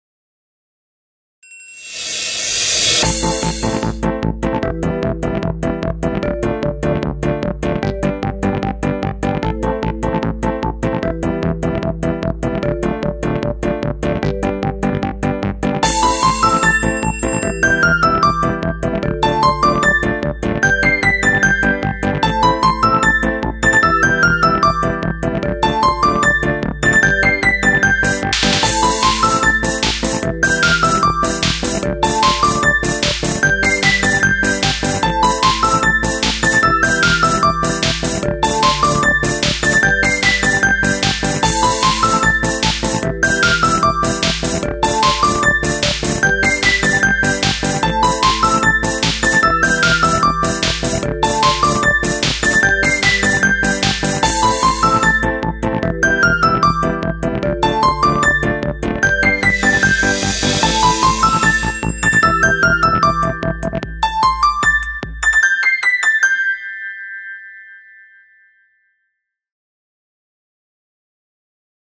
BGM
アップテンポショート明るい